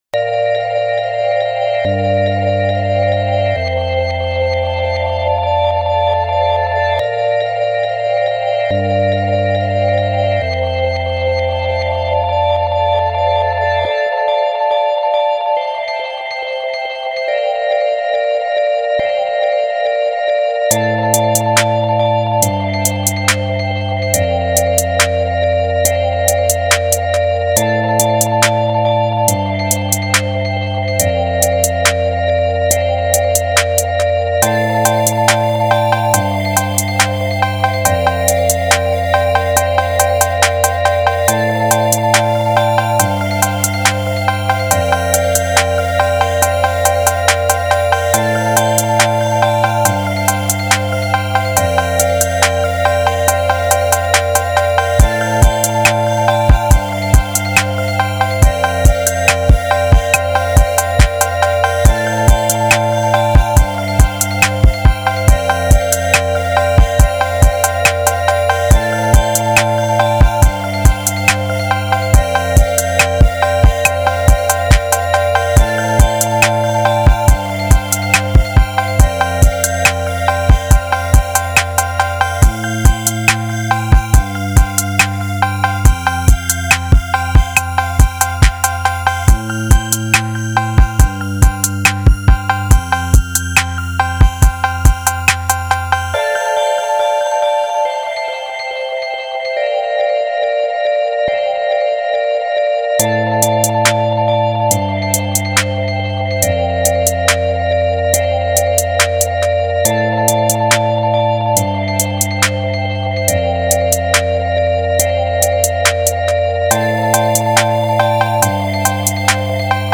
ORIGINAL INSTRUMENTALS